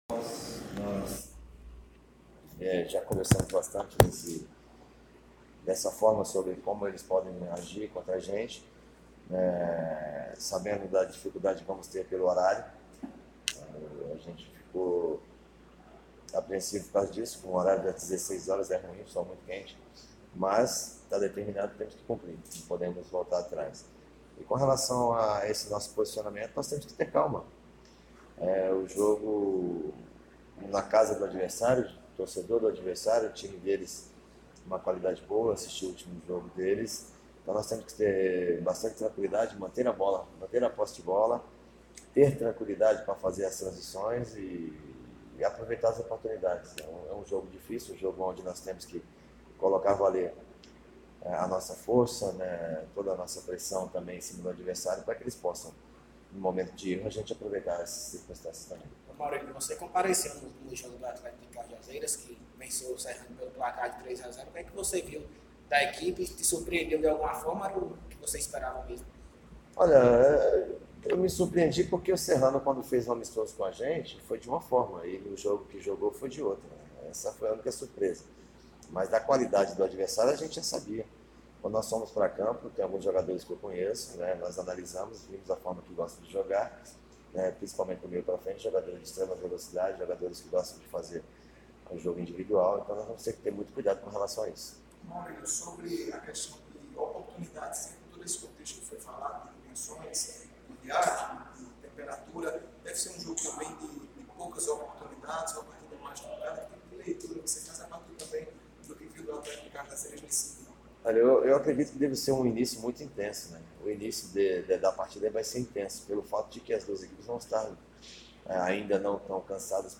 Futebol